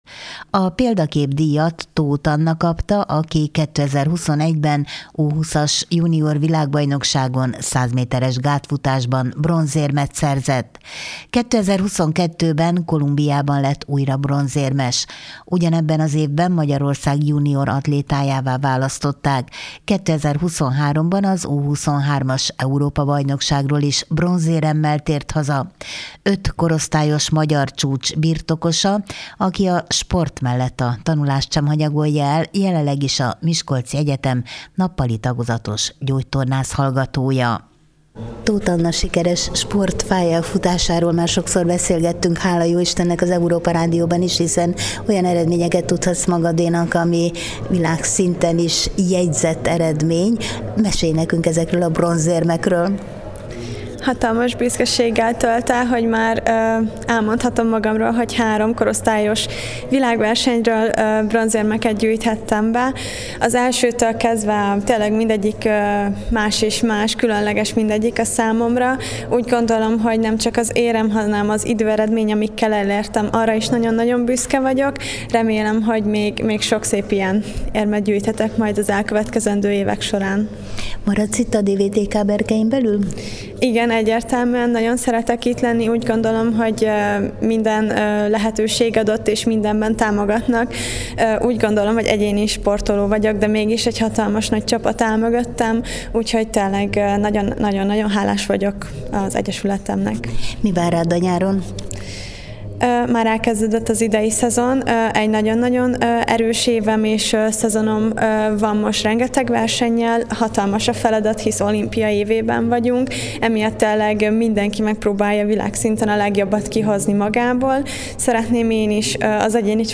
megyenap_dijatadas.mp3